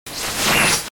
Однако мой winamp его играет, там нечто вроде звукового эффекта тщщ-тиу-тиу, "свипа-перебивки" - такие в новостях радио или джинглах используют.
вот то, что я услышал в проигрывателе winamp.